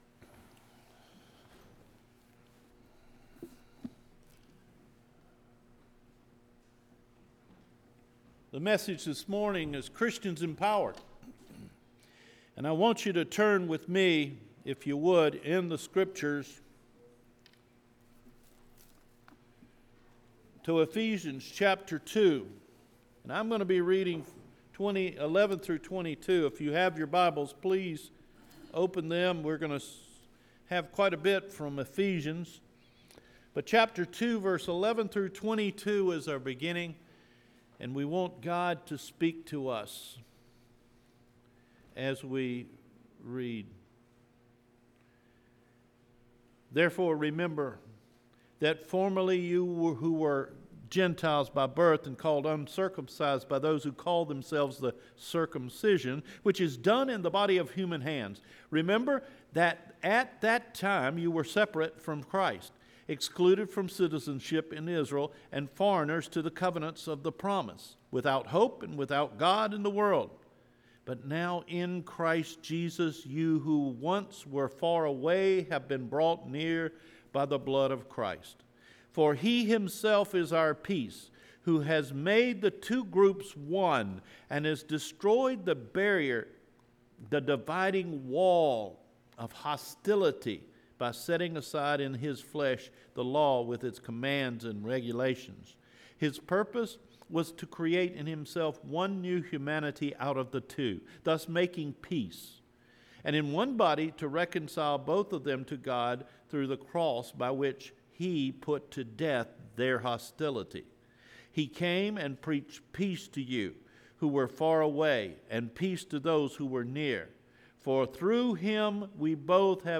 CHRISTIANS EMPOWERED – JUNE 9 SERMON